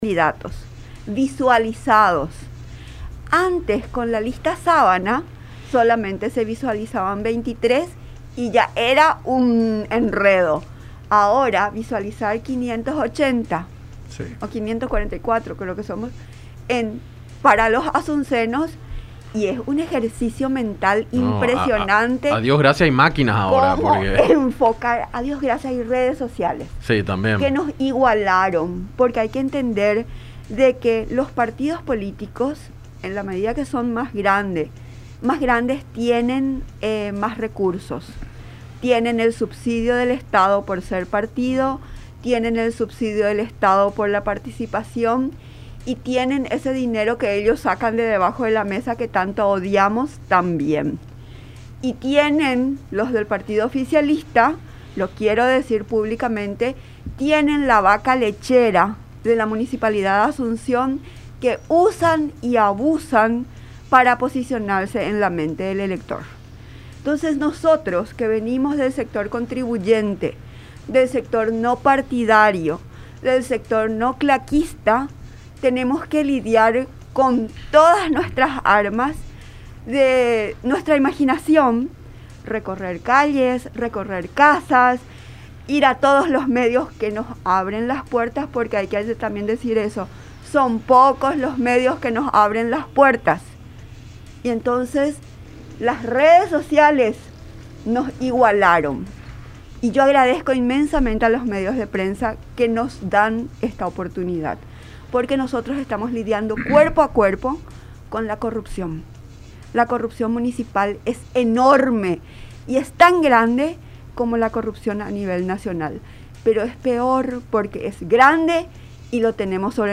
en su visita a los estudios de La Unión, durante el programa Enfoque 800.